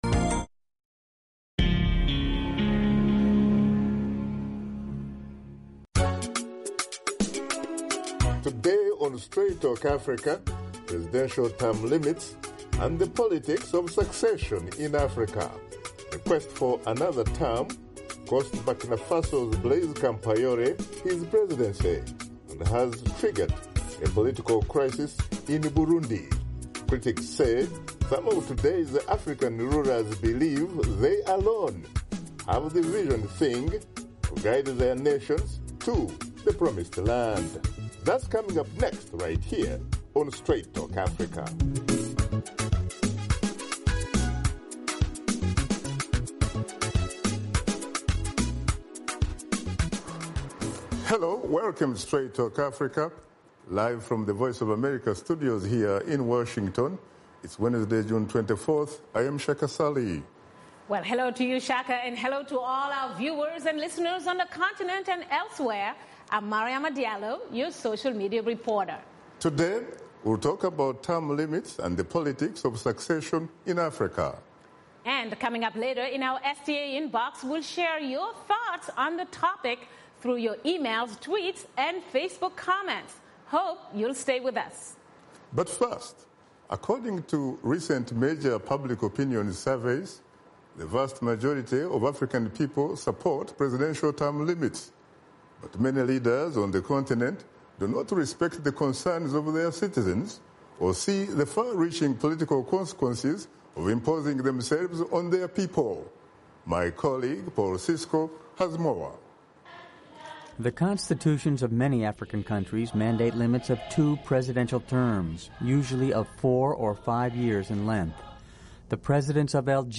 ost Shaka Ssali and his guests examine the reluctance of some African leaders to relinquish power when their terms are nearing an end and politics of succession. Washington Studio Guests: Amama Mbabazi, fmr.